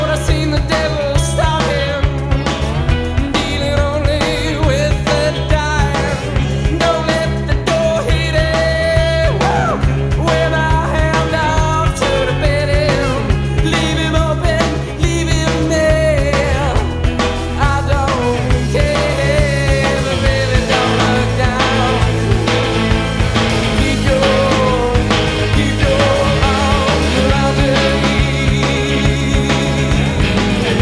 Rock et variétés internationales